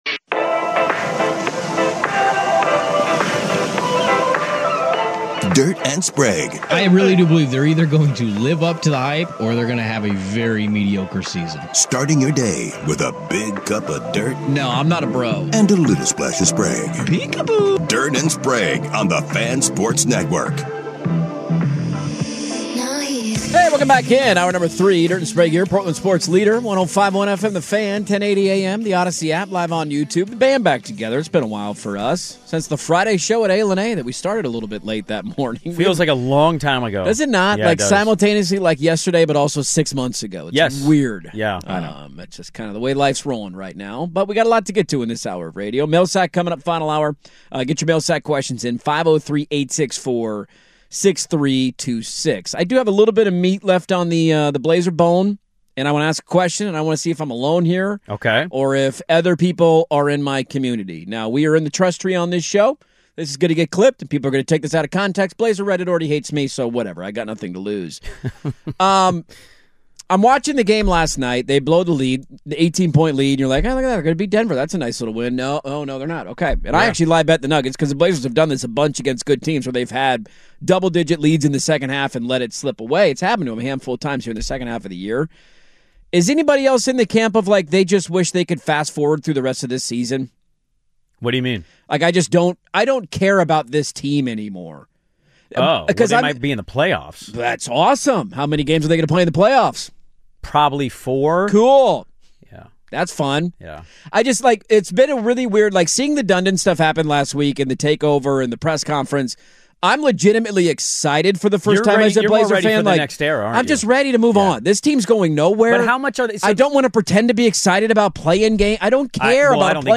Listeners chime in on the best outcome for the rest of this Blazers season...is the NBA's minimum games played requirement backfiring?